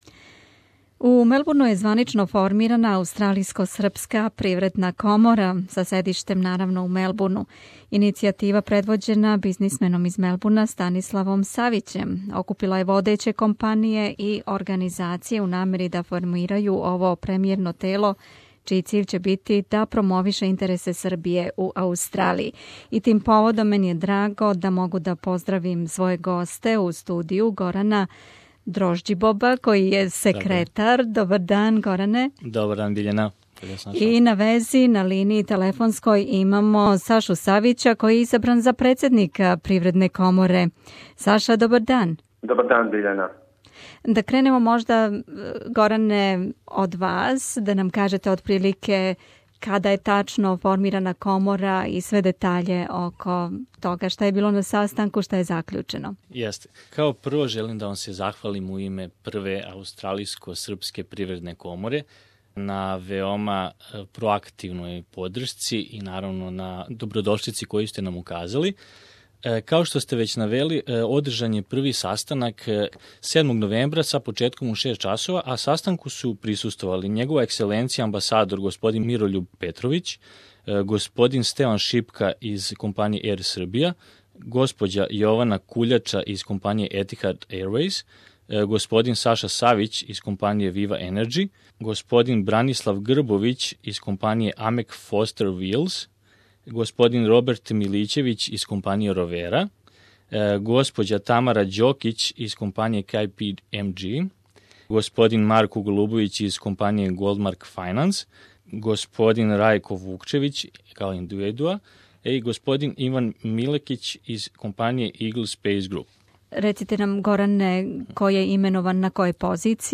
придружили су нам се у студију и путем телефона да кажу више и циљевима и будућем раду.